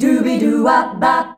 DUBIDUWA C.wav